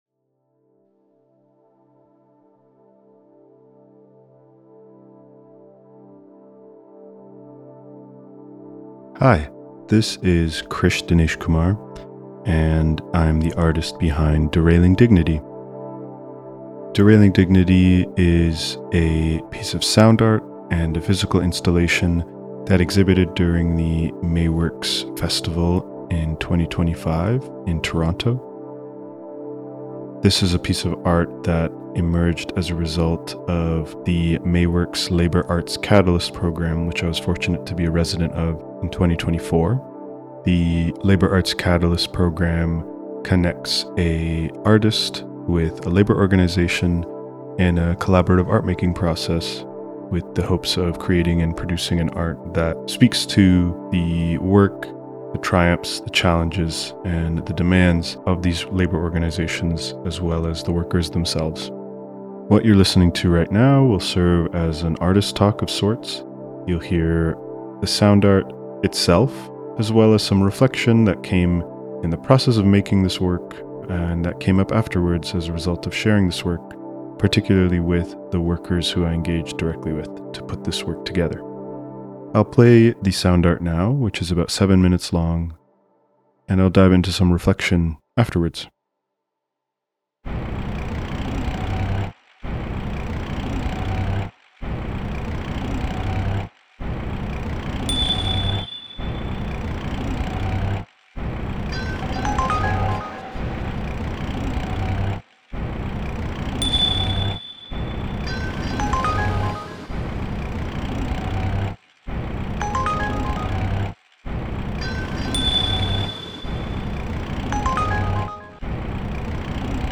LAC-Artist-Talk-Audio-Recording-V2.mp3